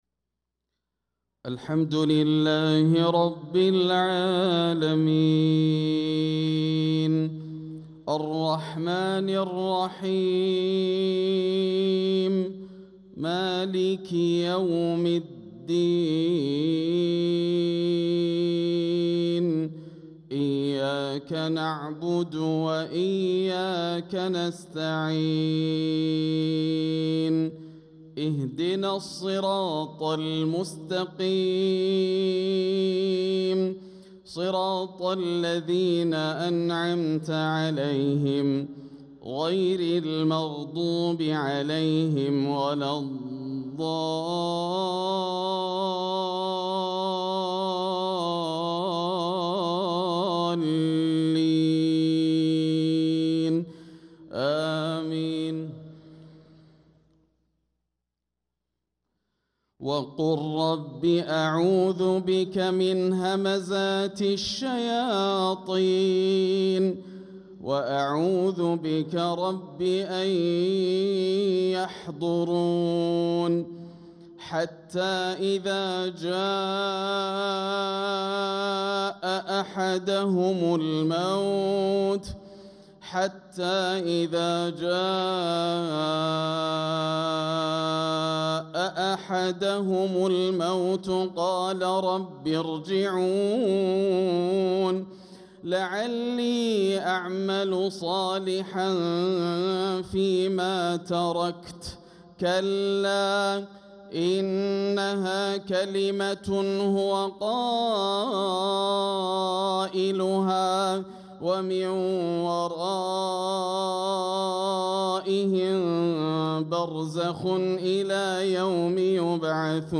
صلاة العشاء للقارئ ياسر الدوسري 21 ربيع الآخر 1446 هـ
تِلَاوَات الْحَرَمَيْن .